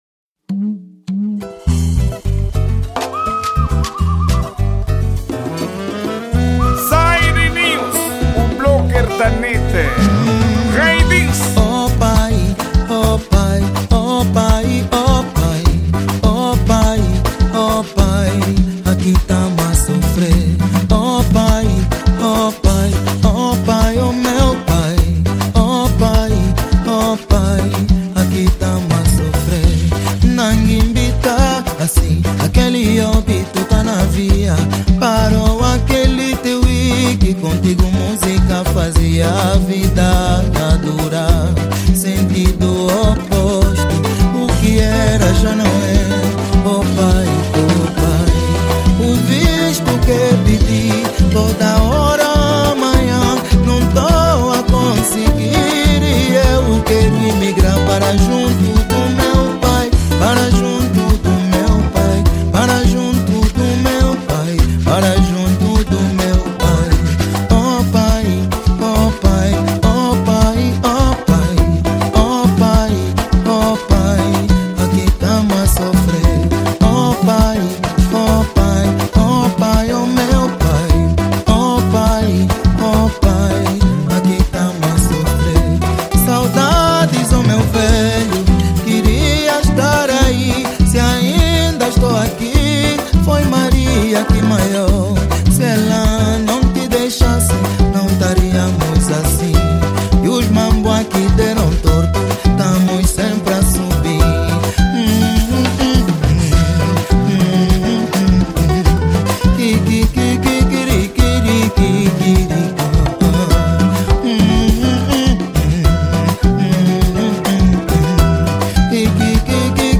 Gênero:Semba